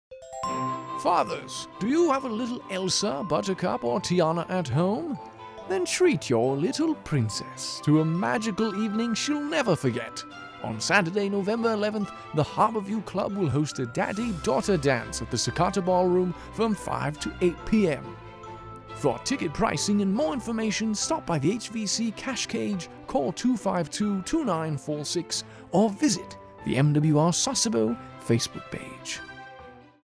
A radio spot for AFN Sasebo, the Eagle, to promote a "Daddy Daughter" dance taking place on November 11, 2023, at the CFAS Harbor View Club.